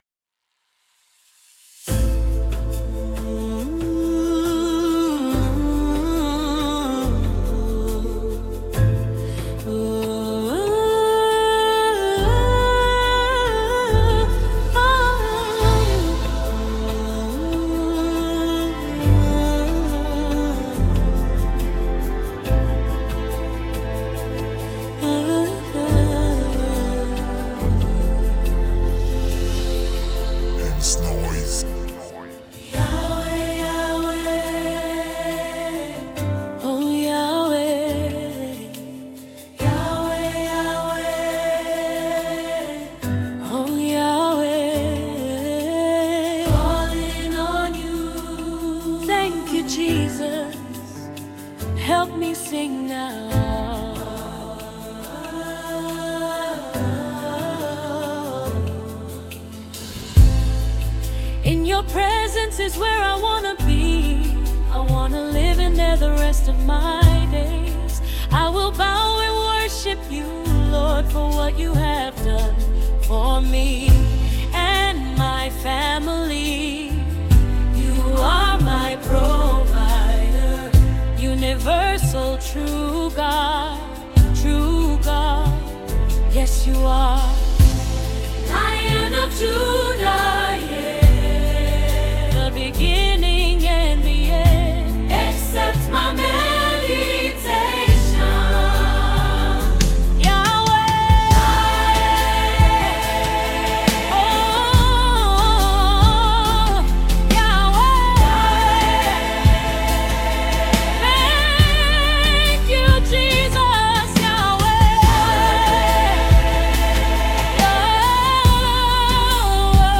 rich melodies, and powerful vocal delivery
gospel sound
the single delivers a polished and inspiring sound